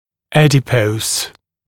[‘ædɪpəus][‘эдипоус]жир; жировой; жирный